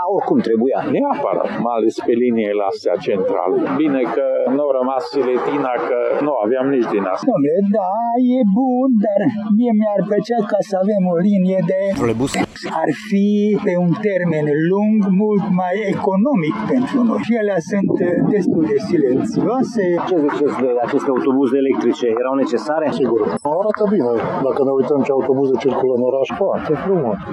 Locuitorii din Tg.Mureș speră ca schimbarea parcului învechit de mașini al transportului local se realizeze cît mai repede: